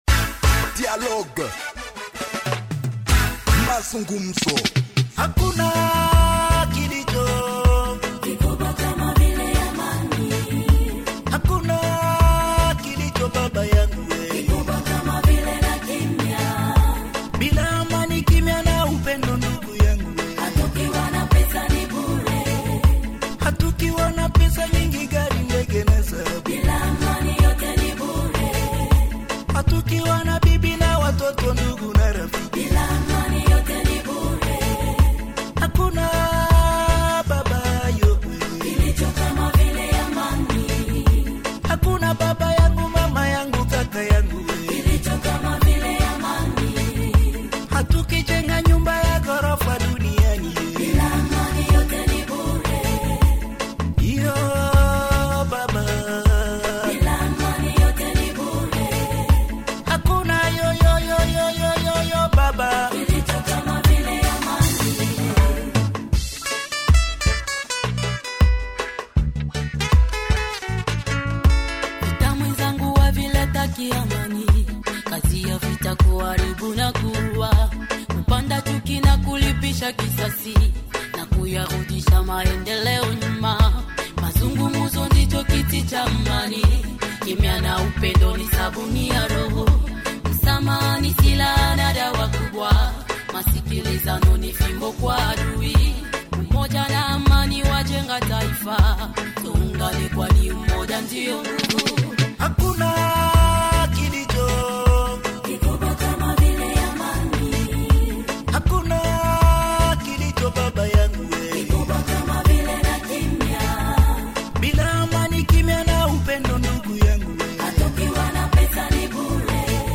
Journal en Français du 09 Juillet 2025 – Radio Maendeleo